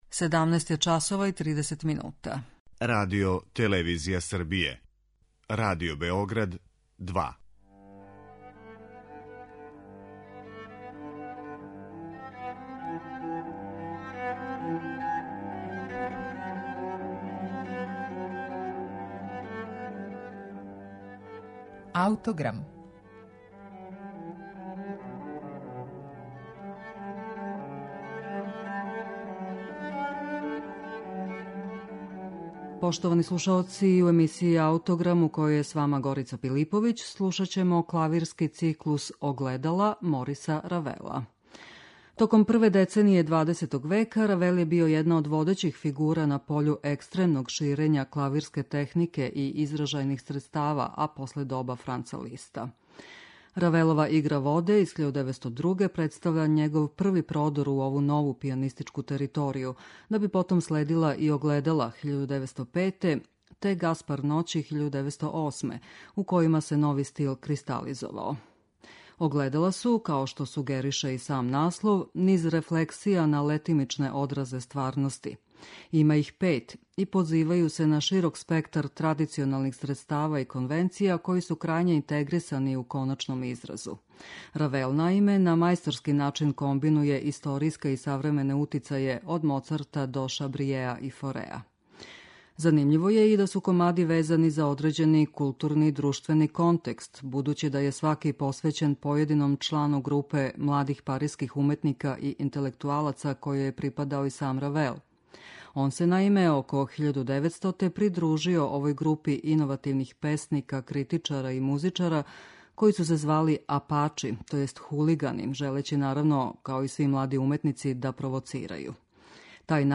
Једна од најзначајнијих клавирских композиција Мориса Равела јесте циклус "Огледала".